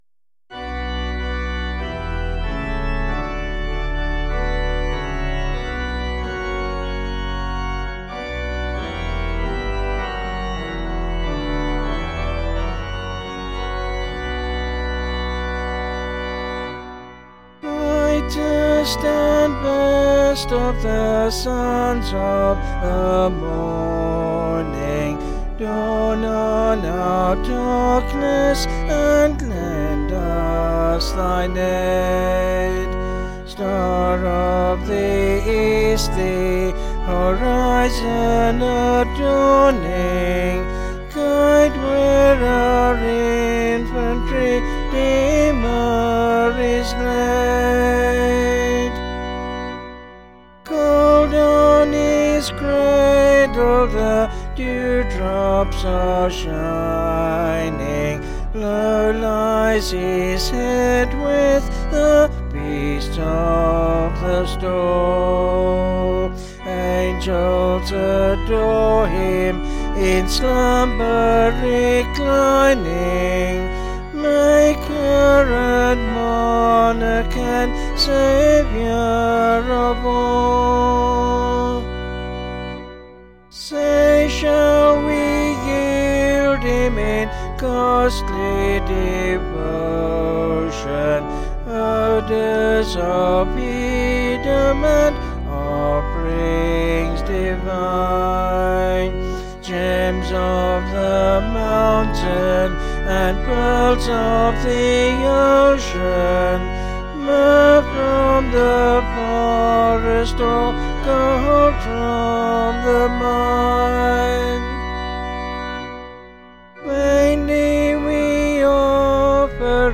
(BH)   5/Bm
Vocals and Organ   703.8kb Sung Lyrics